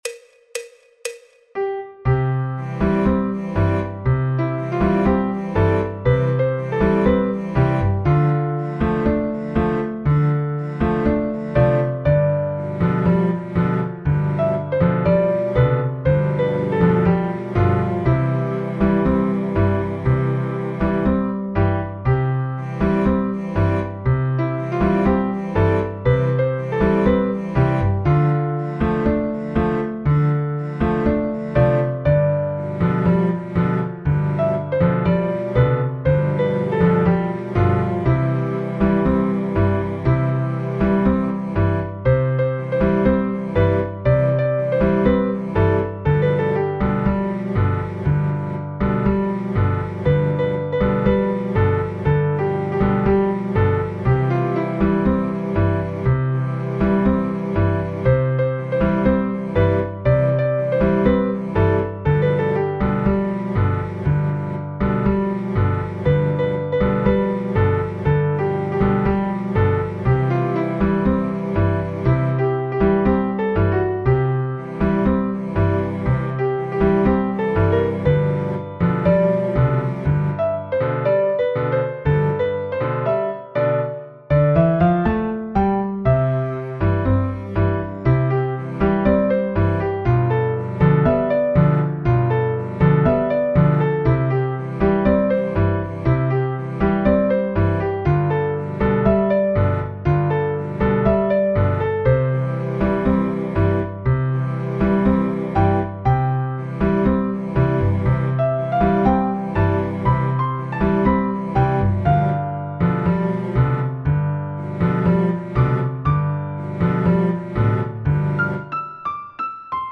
El MIDI tiene la base instrumental de acompañamiento.
Recomendada para clases y profesores de Piano en Do Mayor.